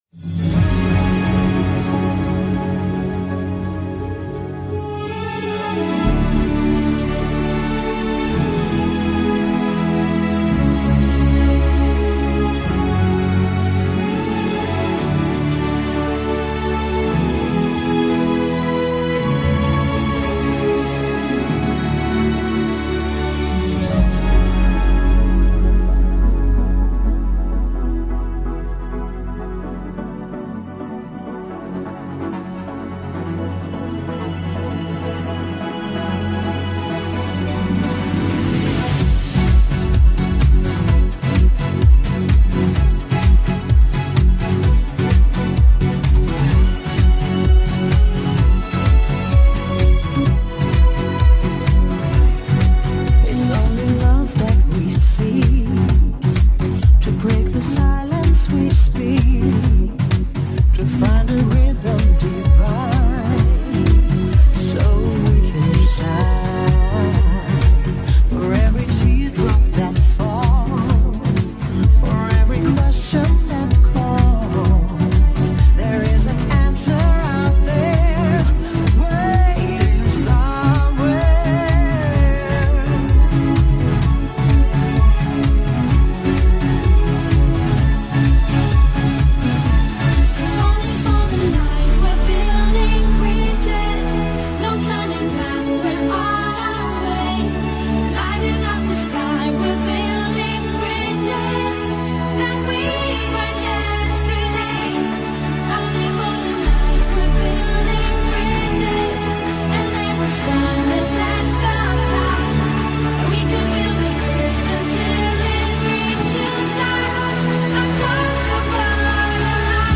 An hour of Austrian music in the mix